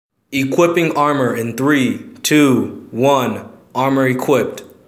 equipSound.wav